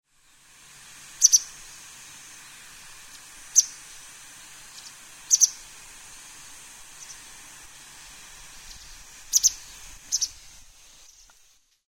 hakusekirei_c1.mp3